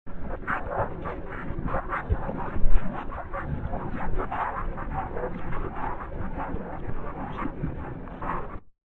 HaltWhisper.mp3